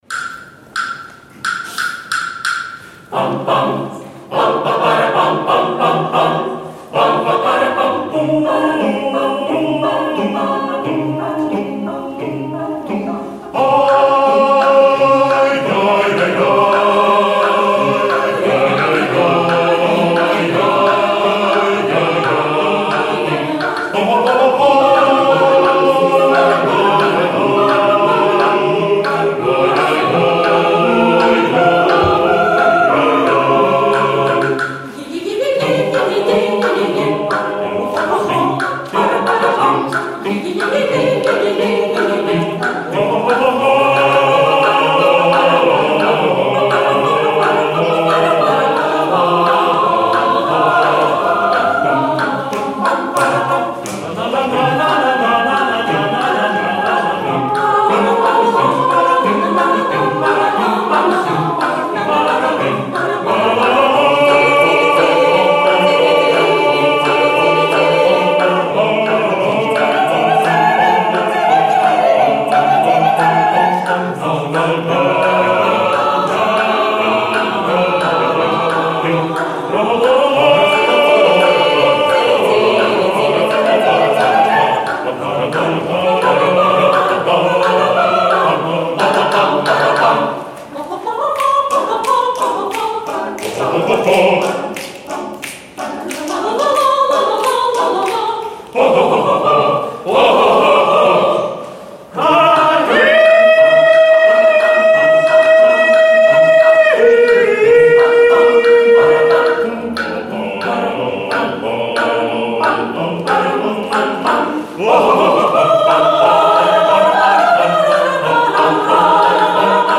Прослушать вариант исполнения: